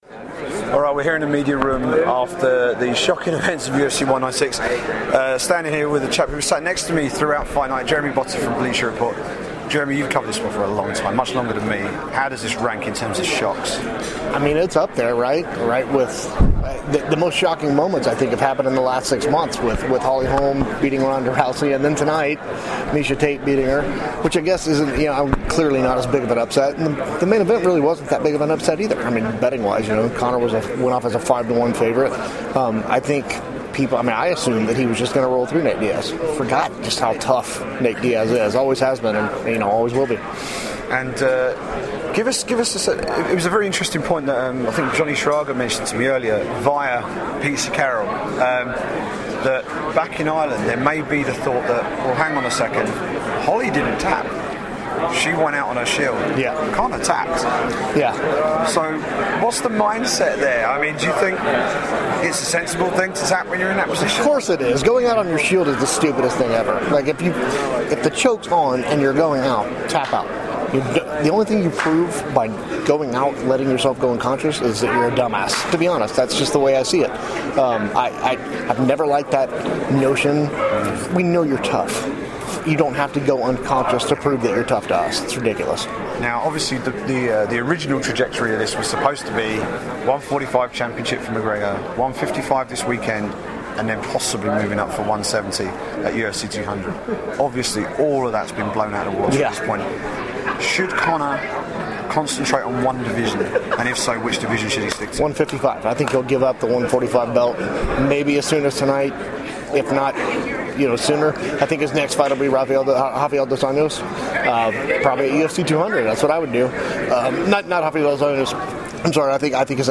UFC 196 Press Room Reaction